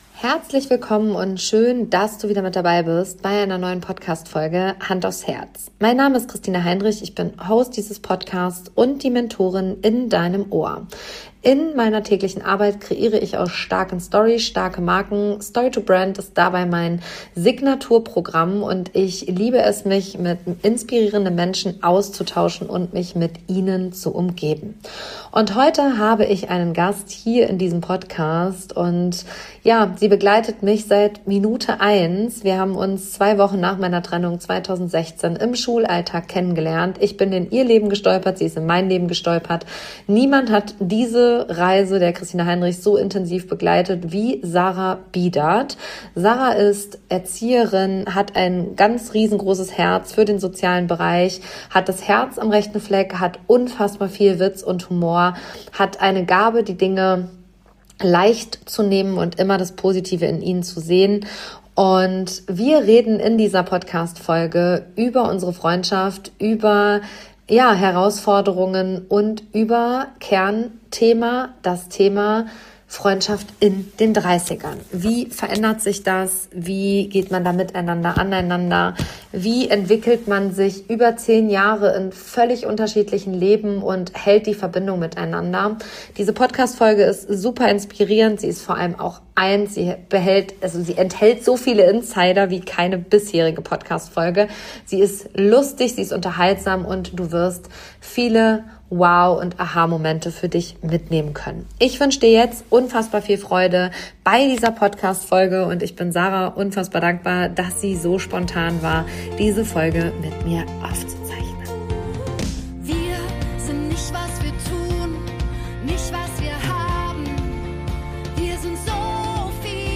Diese Folge ist laut und leise gleichzeitig.